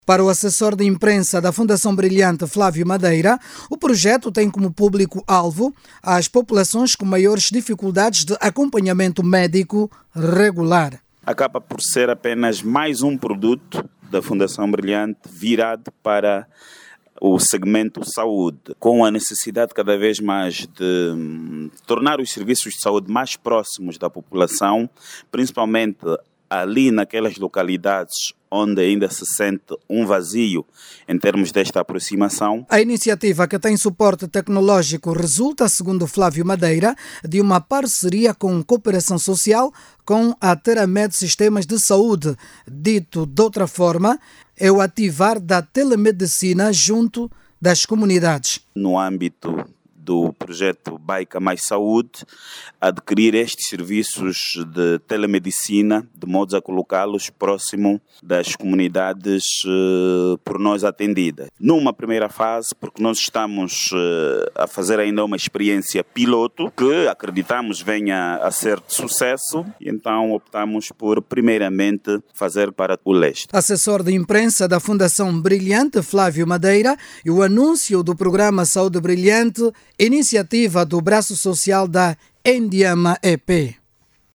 A iniciativa, denominada Programa Saúde Brilhante, é integrada e visa a promoção da saúde, a inclusão social e o apoio às comunidades em situação de maior vulnerabilidade. Ouça o desenvolvimento desta matéria na voz do jornalista